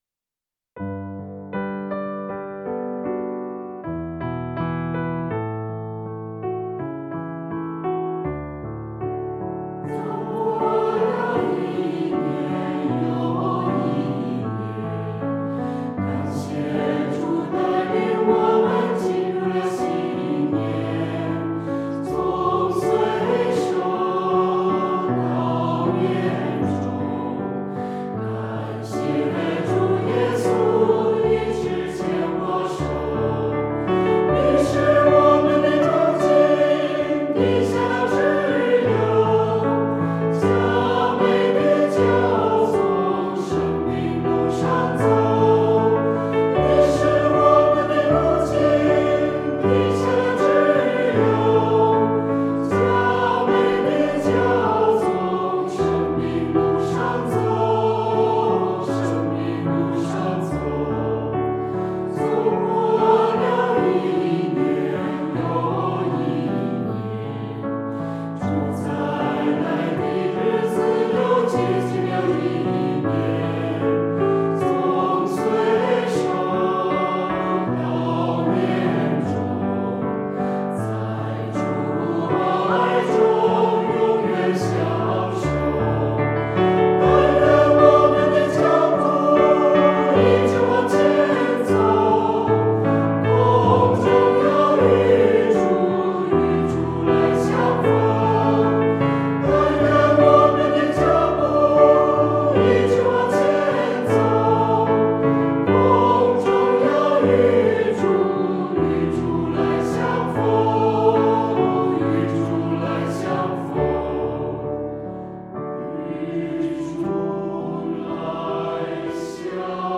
祝福歌：从岁首到年终（197，新603）